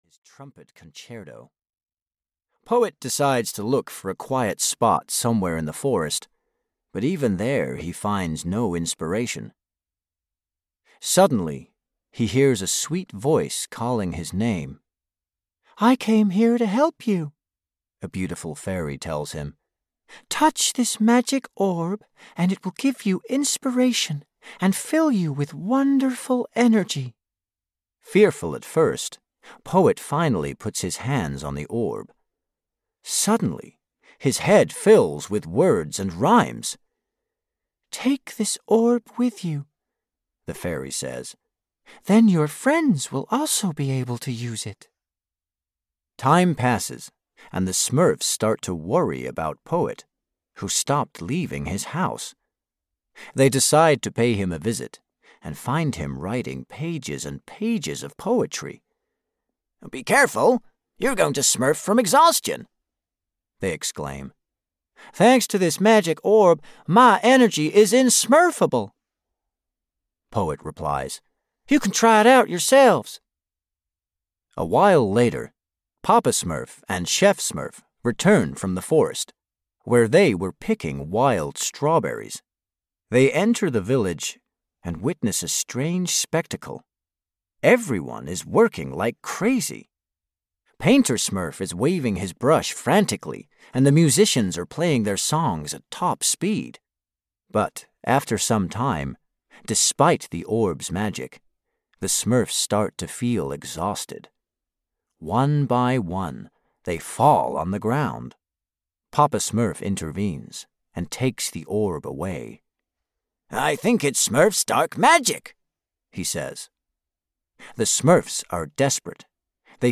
Smurfs: Storytime Collection 5 (EN) audiokniha
Ukázka z knihy